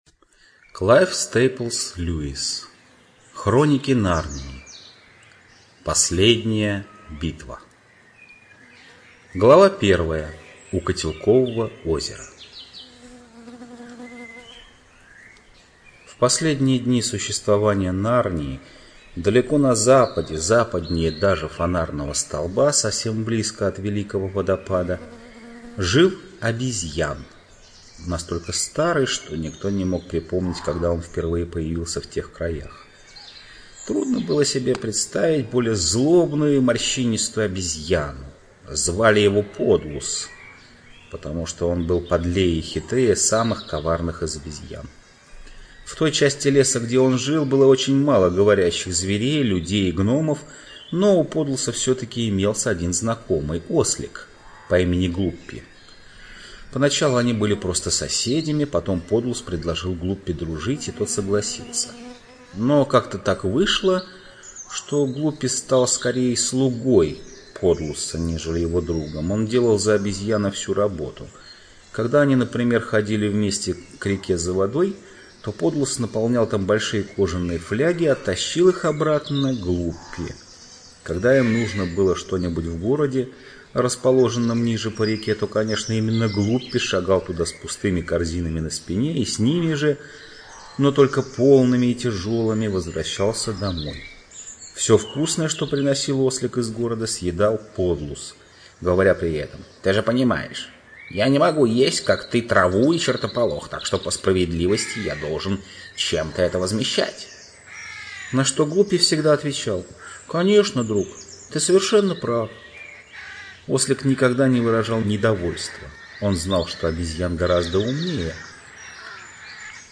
Жанр: Детская литература, фэнтези